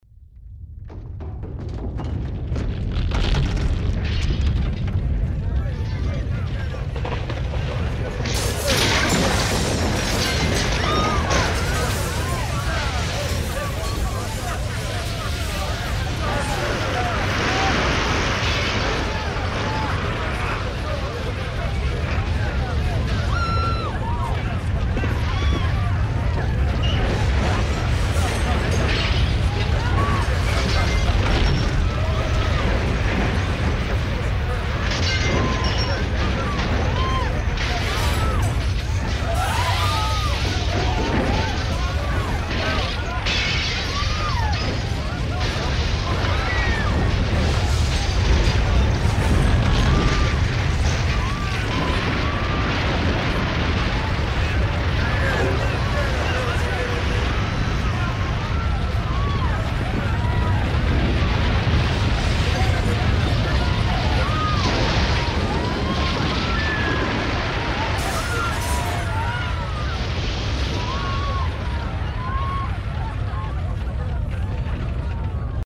На этой странице собраны звуки землетрясений разной интенсивности: от глухих подземных толчков до разрушительных катаклизмов.
Землетрясение и паника среди людей